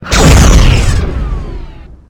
youhit6.ogg